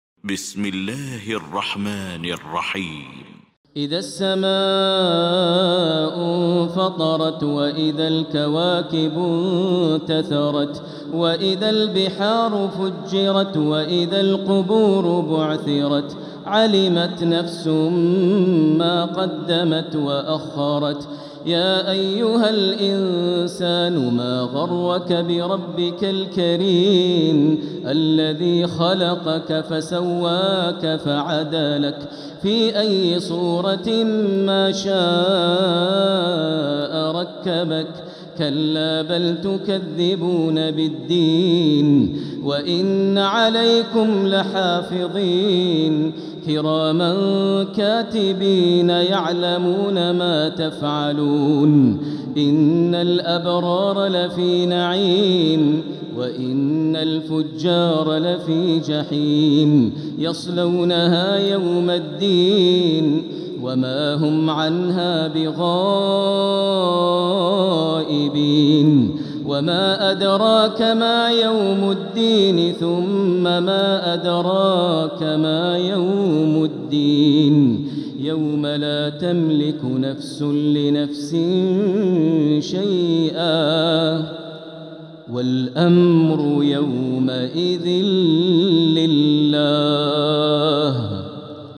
المكان: المسجد الحرام الشيخ: فضيلة الشيخ ماهر المعيقلي فضيلة الشيخ ماهر المعيقلي الانفطار